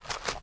High Quality Footsteps / Dirt
STEPS Dirt, Walk 09.wav